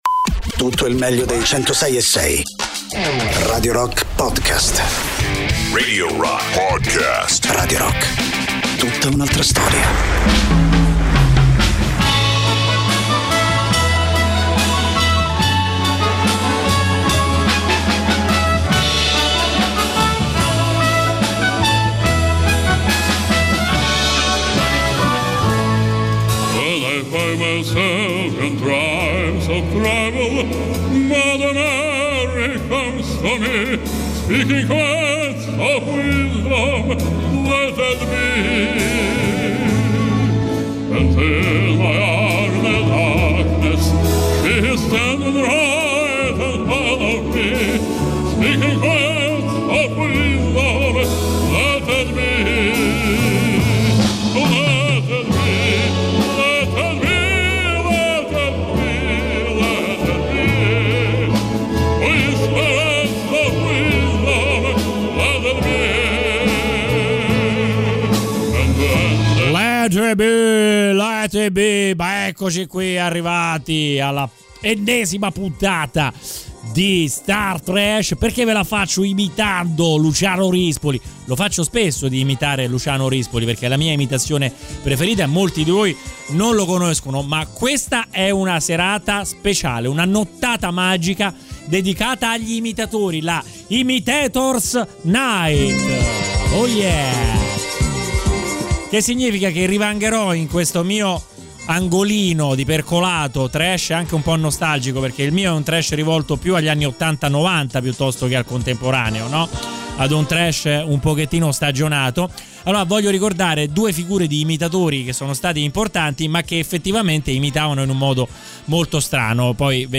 In onda ogni domenica dalle 23.00 alle 24.00 sui 106.6 di Radio Rock.